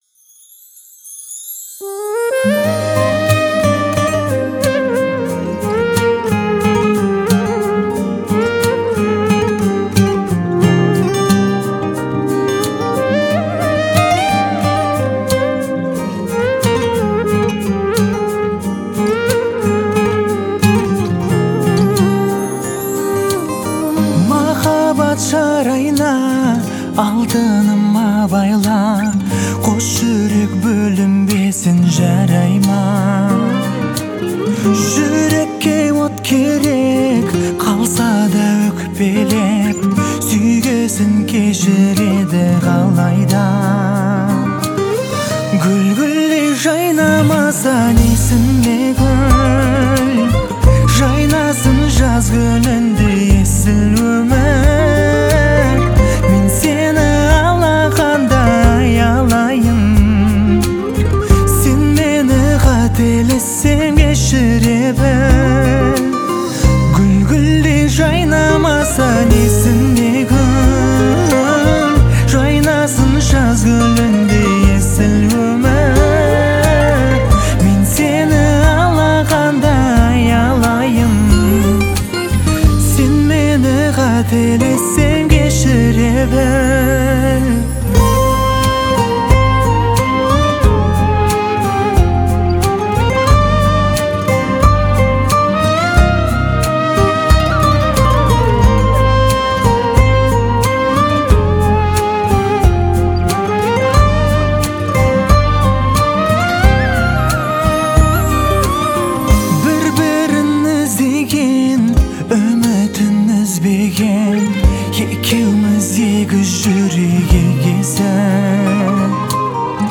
это трогательная песня в жанре поп
используя мягкий вокал и гармоничное звучание